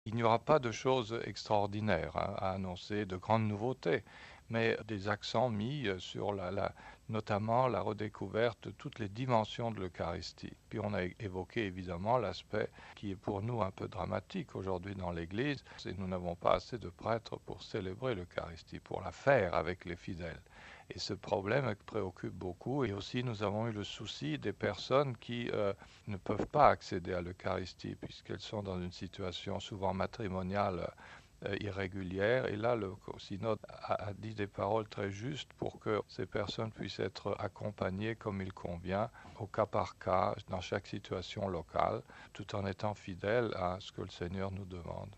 Que faut-il en attendre ? nous avons posé la question à l’évêque de Dijon Mgr Roland Minnerath, secrétaire spécial du synode RealAudio